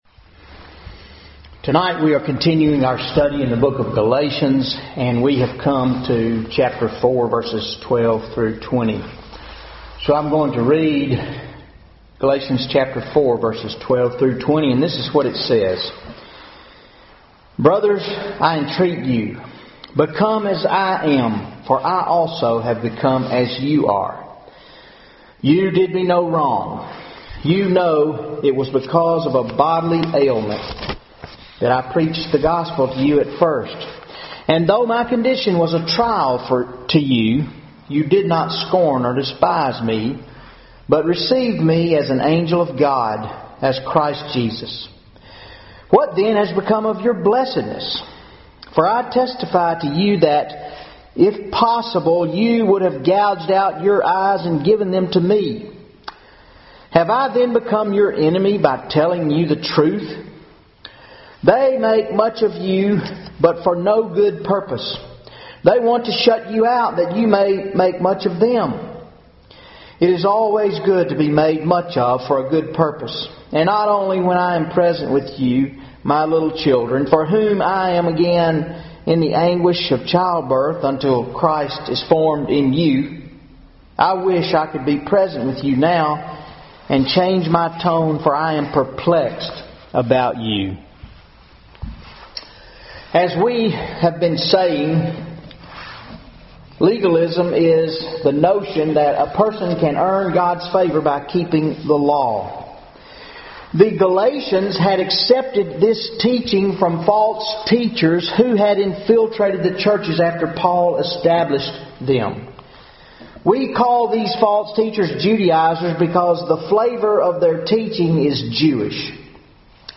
Wednesday Night Bible Study 02/11/15 Galations 4:12-20 Legalism Contradicts Apostolic Friendship